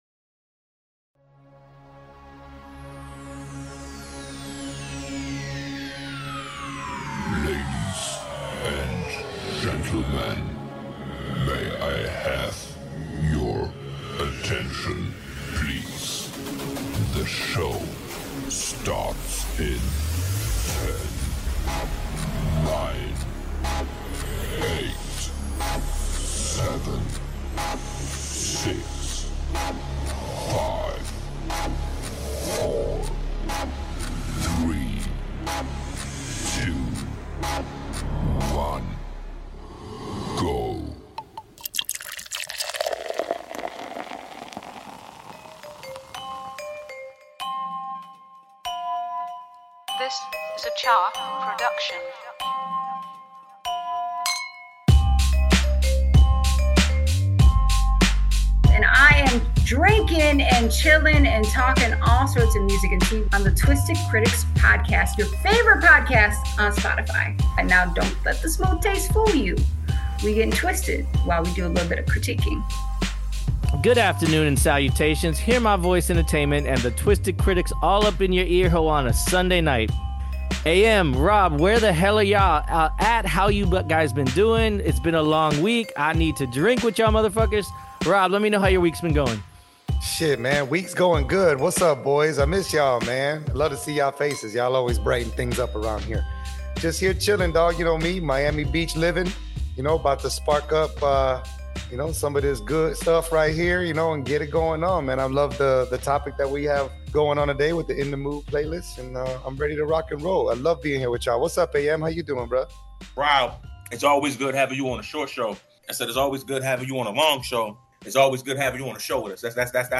Be a guest on this podcast Language: en Genres: Music , Music Commentary , Music Interviews Contact email: Get it Feed URL: Get it iTunes ID: Get it Get all podcast data Listen Now...